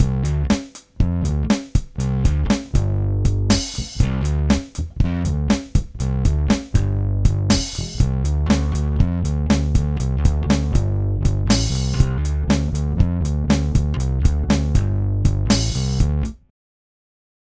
Obwohl f�r Gitarre entworfen, eignet sich die Mercer-Box aufgrund ihres breiten Frequenzganges auch sehr gut f�r rockige E-Bass-Sounds. Fuzz und Love sind zwei getrennte, hintereinandergeschaltete Zerrstufen mit unterschiedlicher Charakteristik. Fuzz erinnert mehr an ein Fuzzpedal (Ach was!?) und Love an einen aufgedrehten Marshallamp.
mercerbox_4_bass.mp3